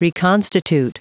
Prononciation du mot reconstitute en anglais (fichier audio)